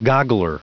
Prononciation du mot goggler en anglais (fichier audio)
Prononciation du mot : goggler